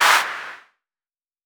Tr8 Clap 02.wav